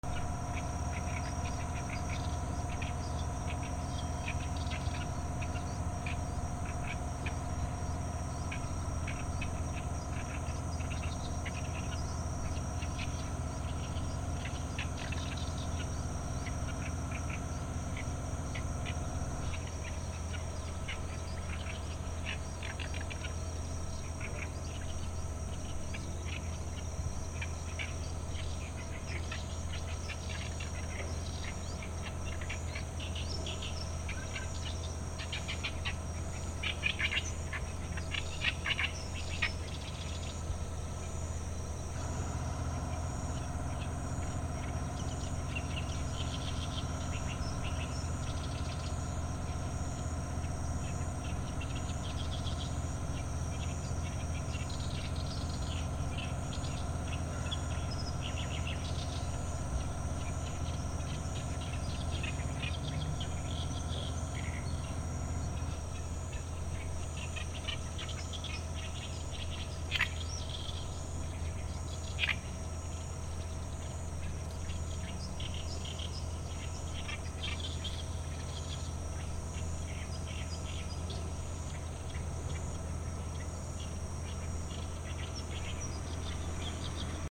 / B｜環境音(自然) / B-35 ｜カエル(蛙)
カエル(蛙)の鳴き声
沼 NTG2 大阪府八尾